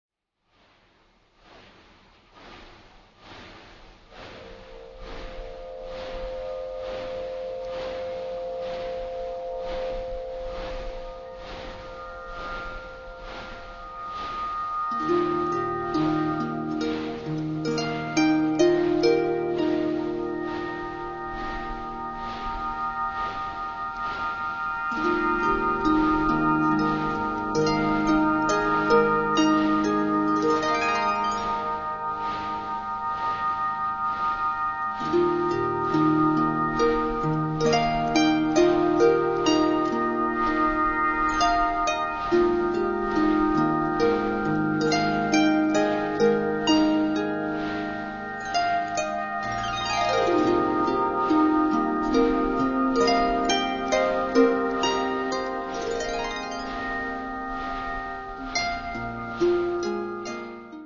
Music for Celtic and Aeolian (wind blown) harps
wind turbine and celtic harp